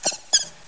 The cries from Chespin to Calyrex are now inserted as compressed cries
grookey.aif